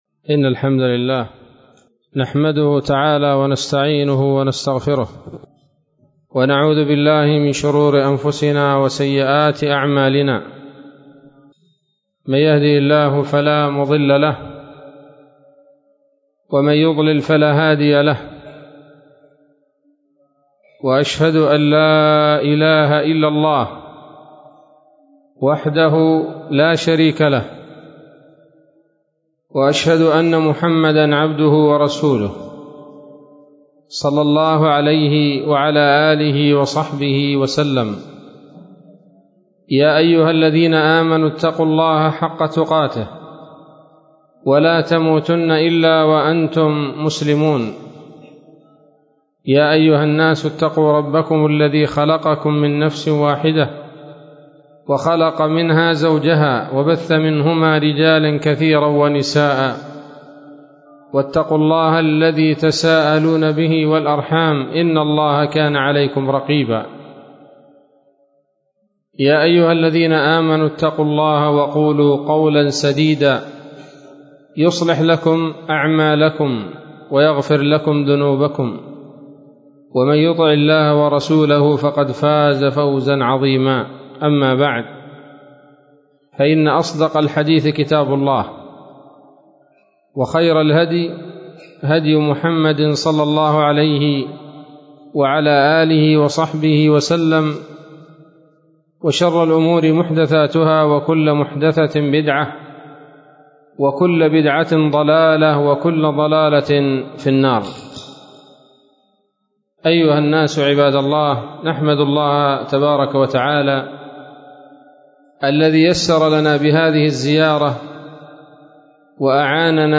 محاضرة بعنوان: (( الهجرة النبوية دروس وعبر )) ليلة السبت 25 من شهر ذي الحجة لعام 1441 هـ، منطقة المحاولة - قرية الرويس